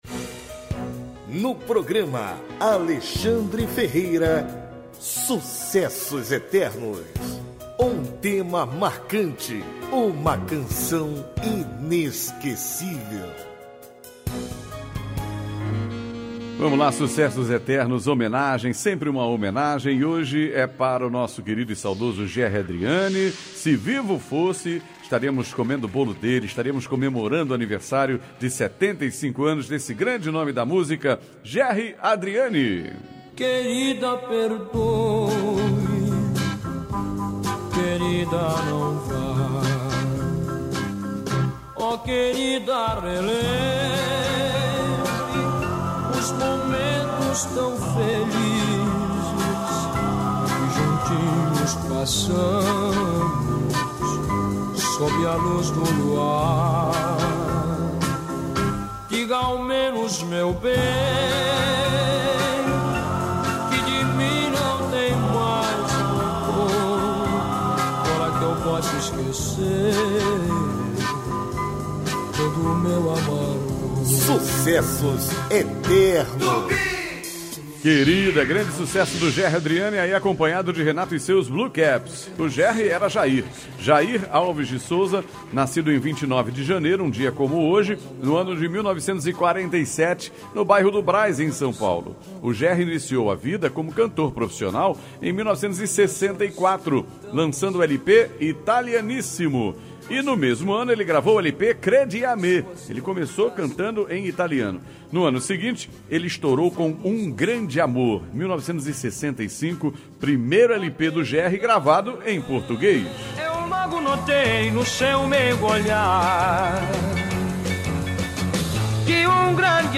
O programa vai ao ar de segunda a sábado, a partir de meia-noite, com muita interação, bate-papo, informação e boa música.